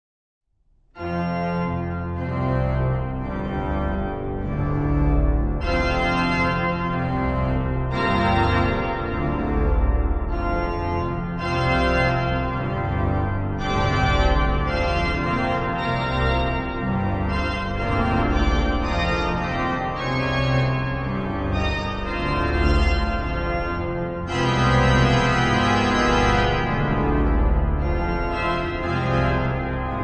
Organy
Organy firmy Eule w Archikatedrze sw, Jana w Warszawie